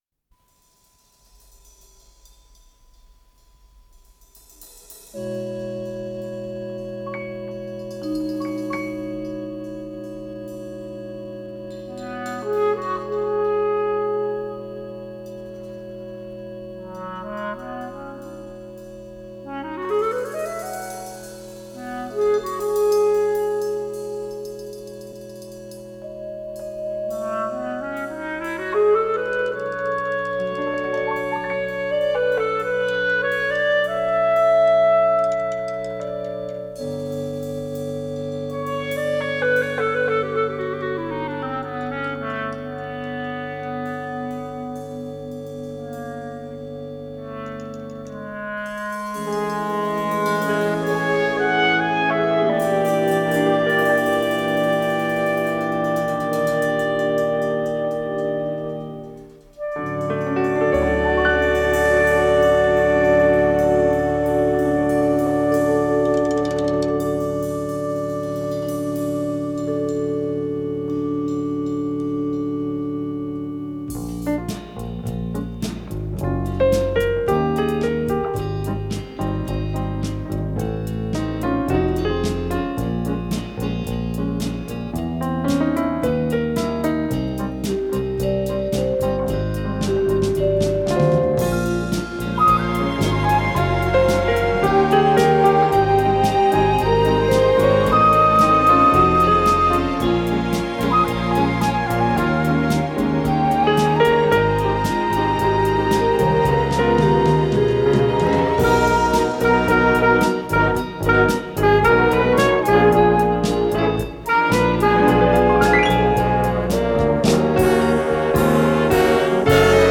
с профессиональной магнитной ленты
фортепиано
Скорость ленты38 см/с